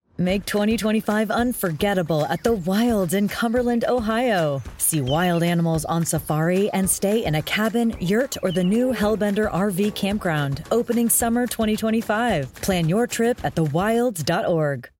If you're looking for an English American female with an engaging, friendly, warm voice to grab and keep your listener's attention, I'm your gal.
The Wilds: radio ad voiceover
Wilds_female_15_FINAL-001.mp3